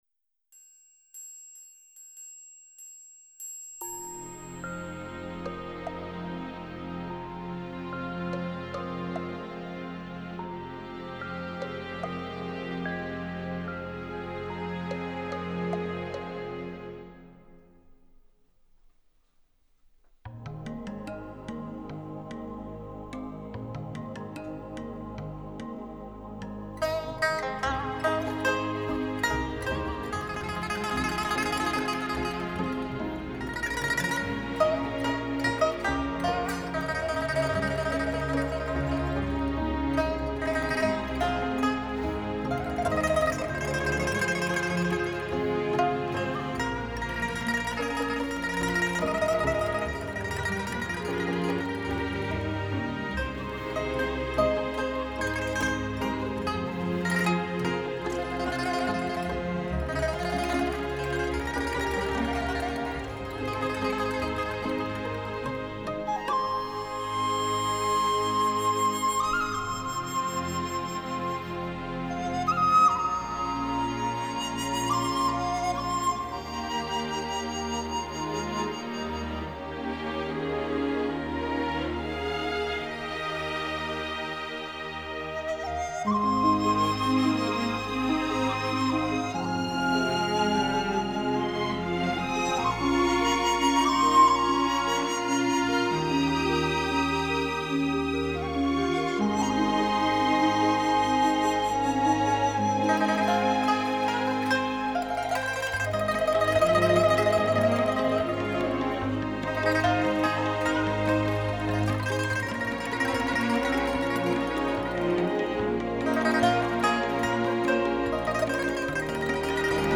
弦乐怀旧的情怀，二胡与爱尔兰竖笛的对唱显得温馨宁静，音乐的高潮部分由单纯到辉煌，由空灵到温暖。